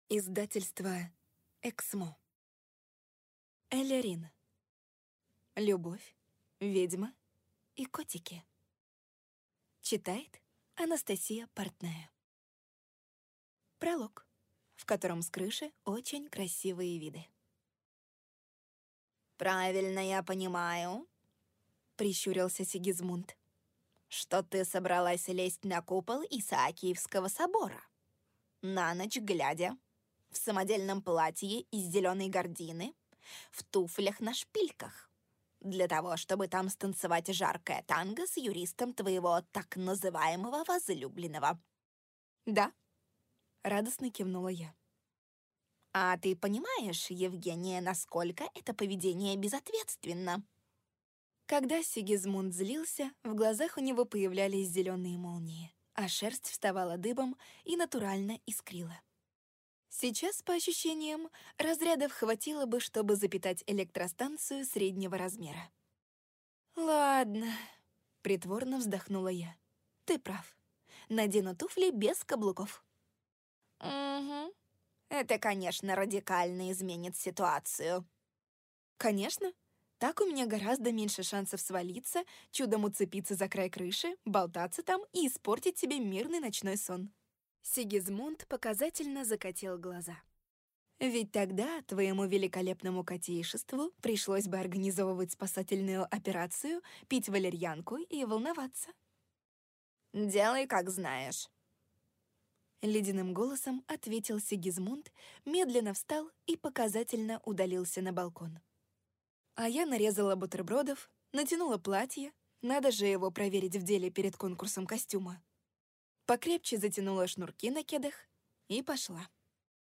Аудиокнига Любовь, ведьма и котики | Библиотека аудиокниг